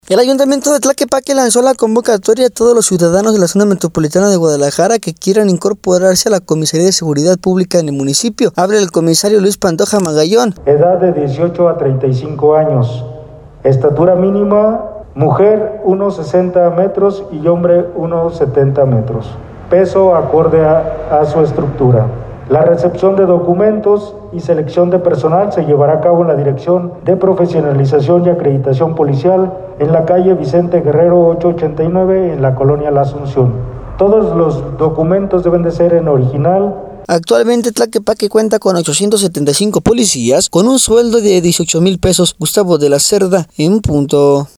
El ayuntamiento de Tlaquepaque, lanzó la convocatoria a todos los ciudadanos de la Zona Metropolitana de Guadalajara que quieran incorporarse a la Comisaría de Seguridad Pública del municipio. Habla el comisario, Luis Pantoja Magallón: